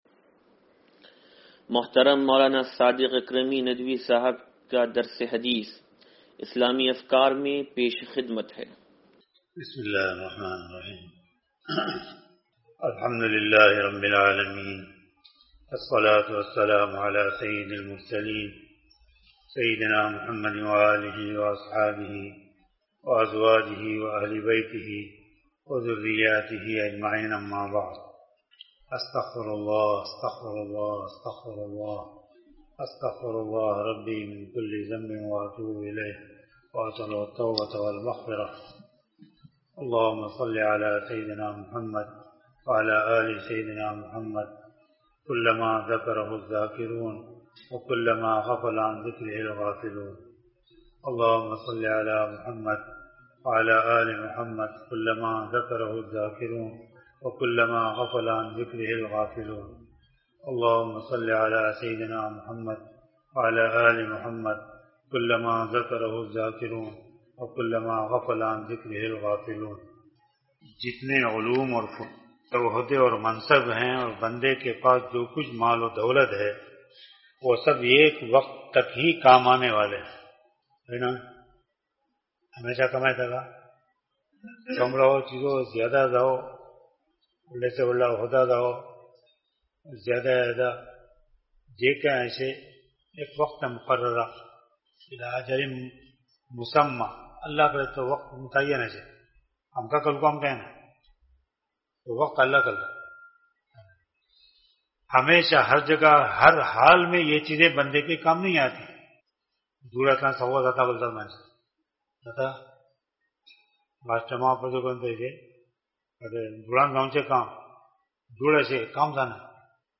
درس حدیث نمبر 0487
درس-حدیث-نمبر-0487-2.mp3